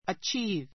achieve A2 ətʃíːv ア チ ー ヴ 動詞 （努力して） 成し遂 と げる, （目的・目標を） 達成する; （名声などを） 手に入れる achieve one's goal in life achieve one's goal in life 人生の目標を達成する She achieved fame as an opera singer.